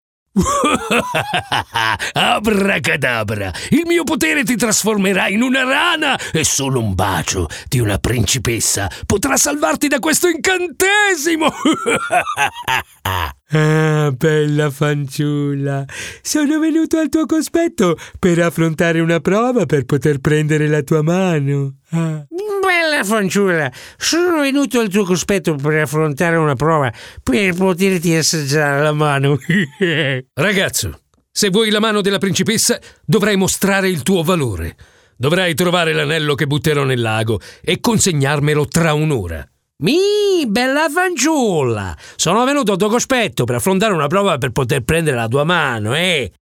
Sprecher italienisch.
Sprechprobe: eLearning (Muttersprache):
Italian voice over artist.